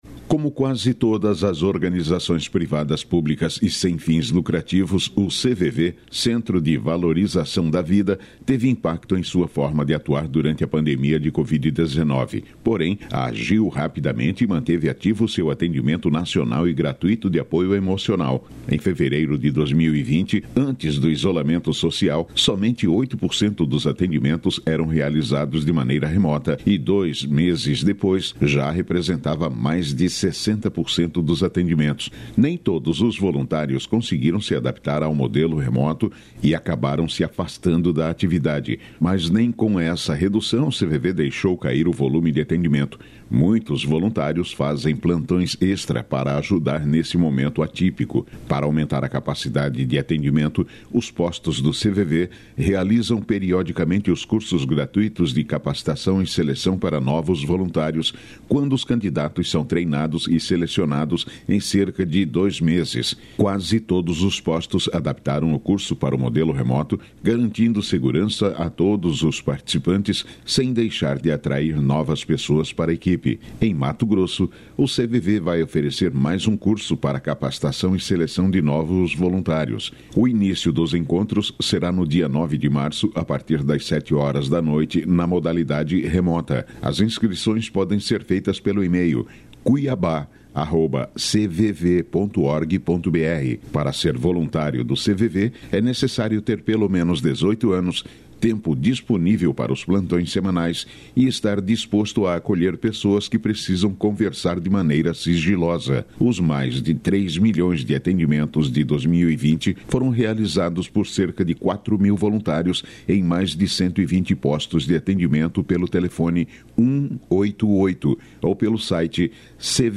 Boletins de MT 14 fev, 2022